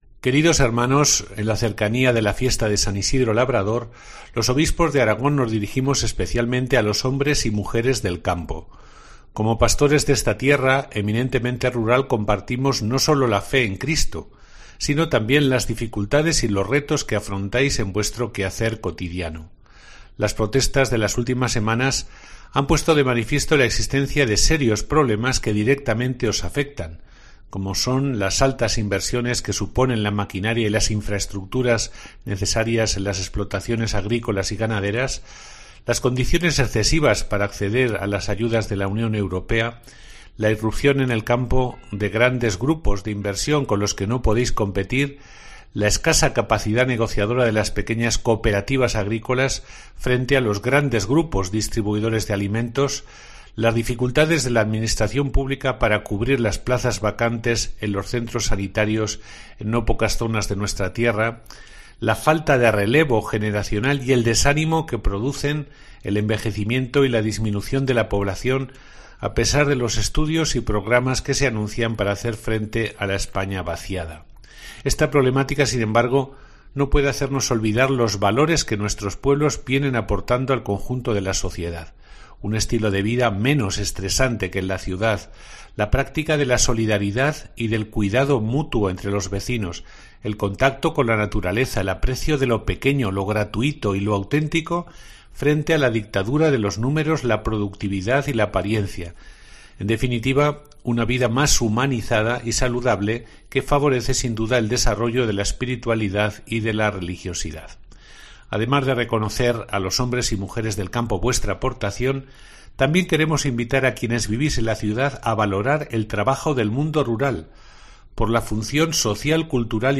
El Obispo de Teruel y Albarracín, D. José Antonio Satué, da lectura a la carta episcopal conjunta